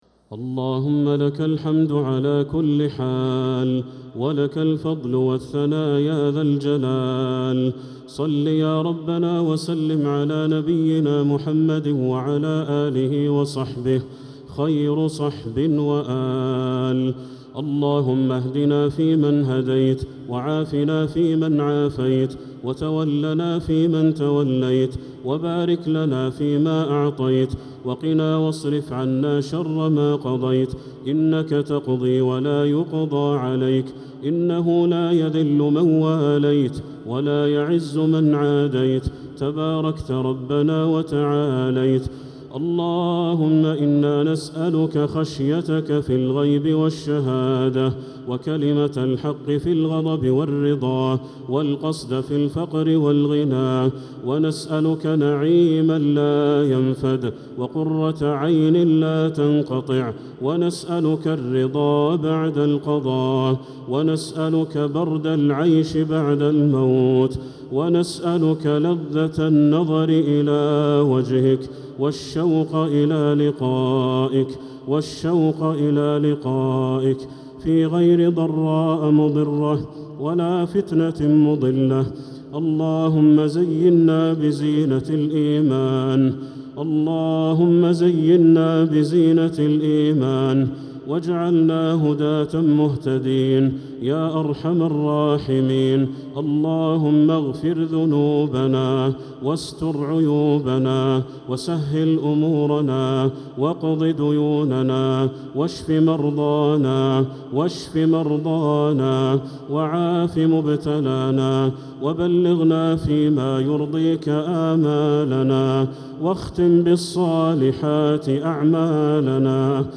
دعاء القنوت ليلة 6 رمضان 1446هـ | Dua 6th night Ramadan 1446H > تراويح الحرم المكي عام 1446 🕋 > التراويح - تلاوات الحرمين